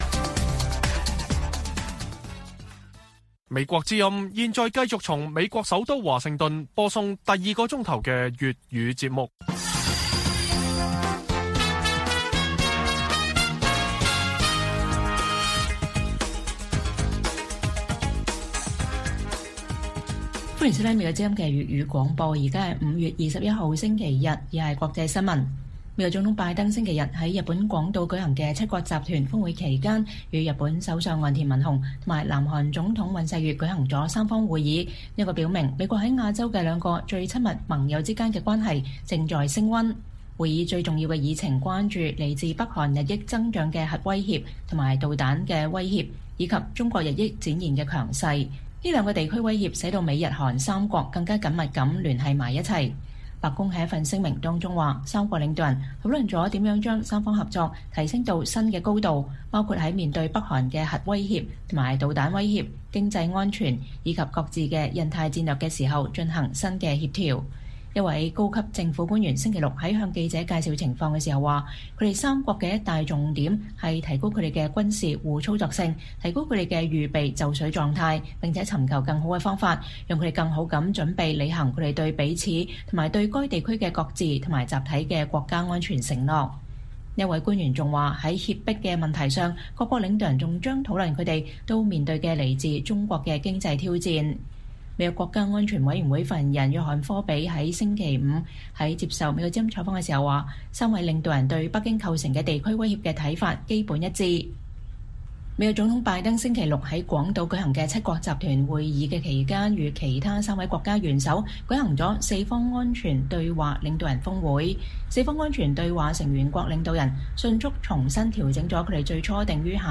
粵語新聞 晚上10-11點: 拜登會見岸田文雄和尹錫悅 討論對抗中國和北韓